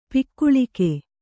♪ pikkuḷike